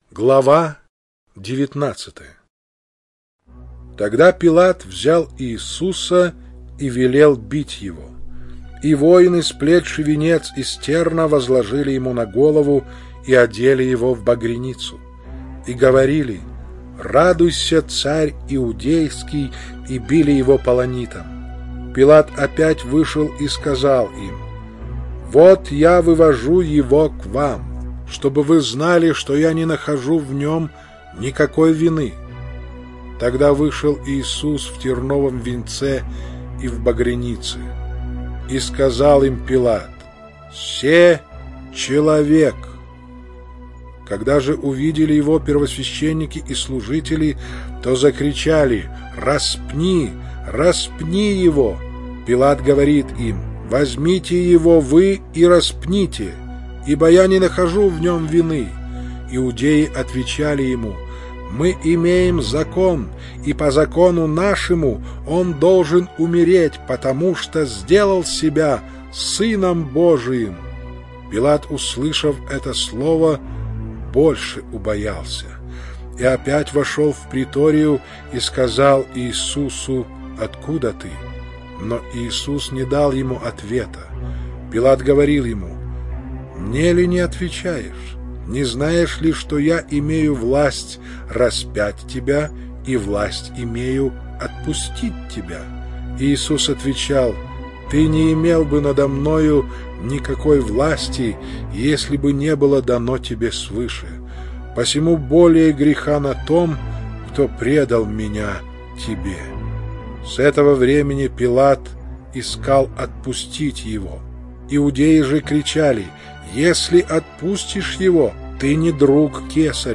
Чтение сопровождается оригинальной музыкой и стерео-эффектами